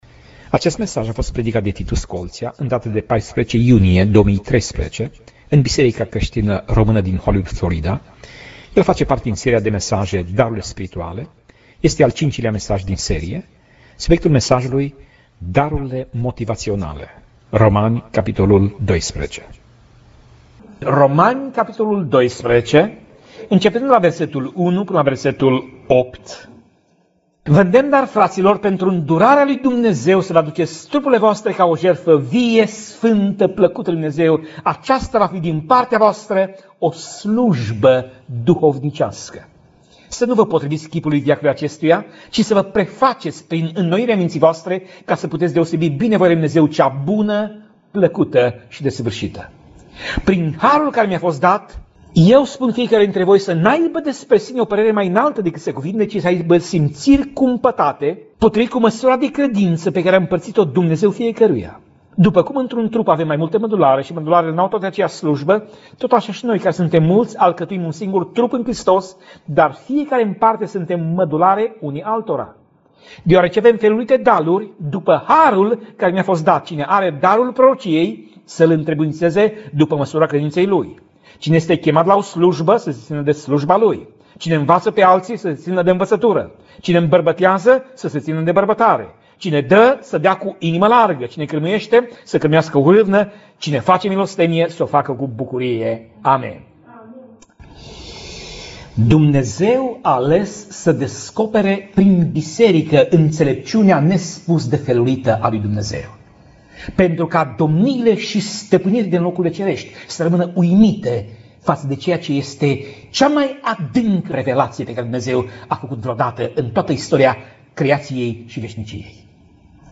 Pasaj Biblie: Romani 12:1 - Romani 12:8 Tip Mesaj: Predica